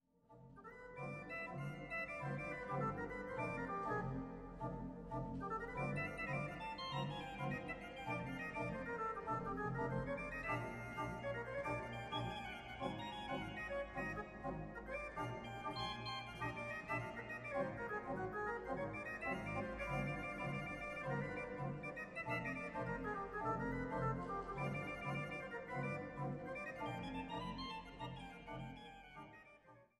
Orgelimprovisationen im Jazzgewand